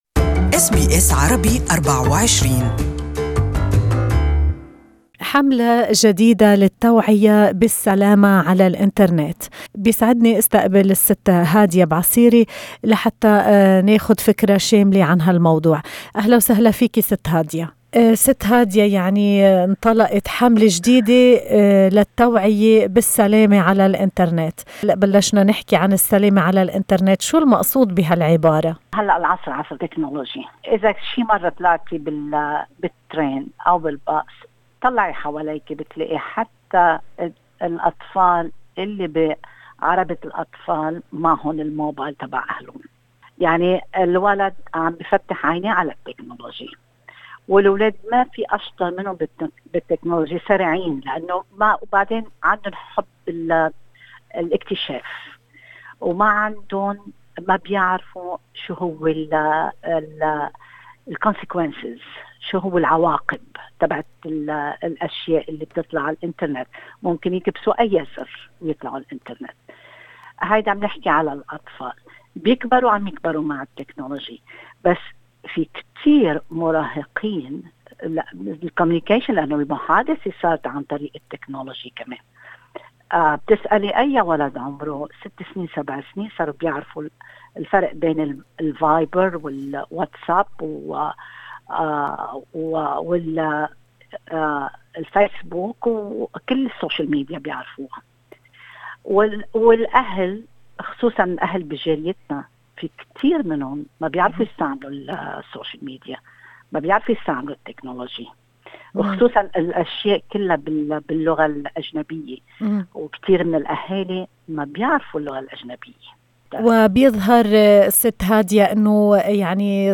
المرشدة الاجتماعية